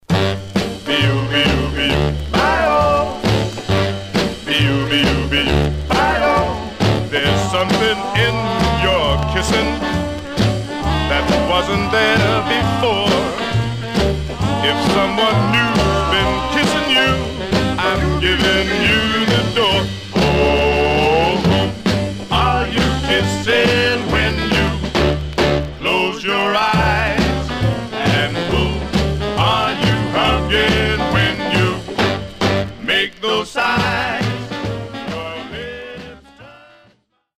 Condition Surface noise/wear Stereo/mono Mono
Rythm and Blues